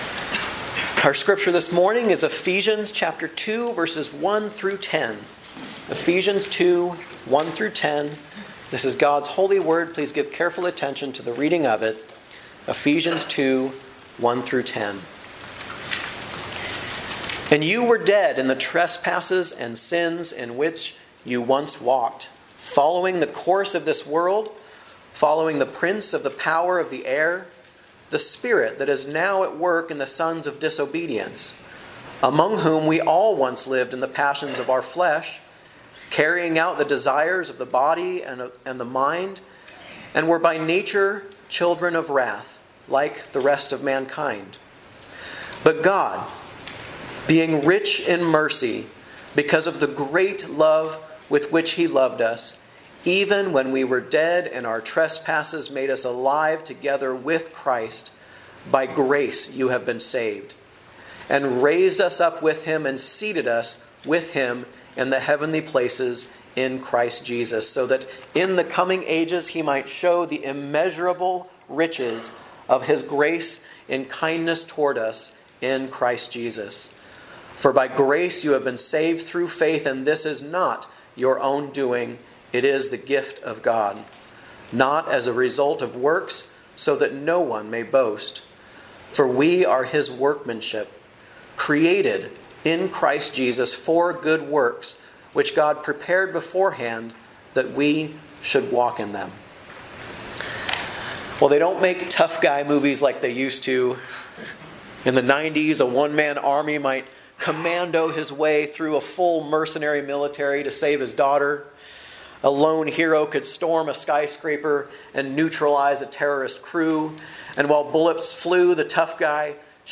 A message from the series "Guest Preacher." 1.